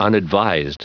Prononciation du mot unadvised en anglais (fichier audio)
Prononciation du mot : unadvised